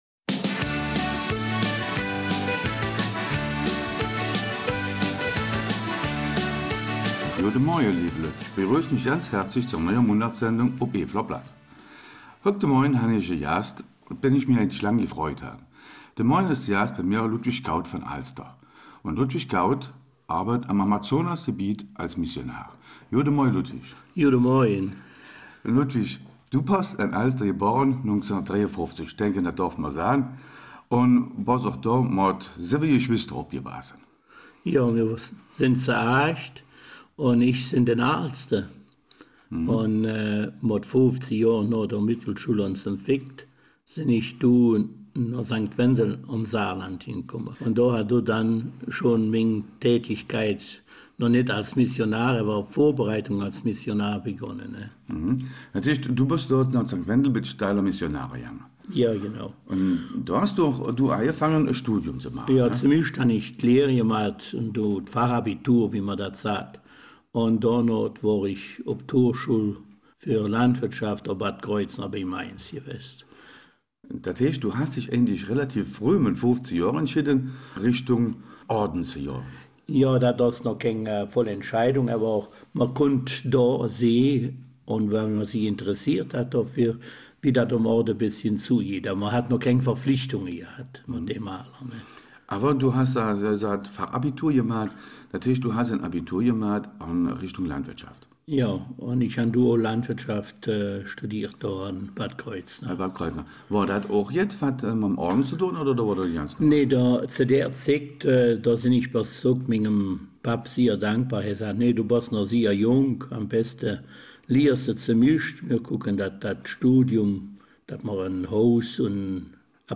Eifeler Mundart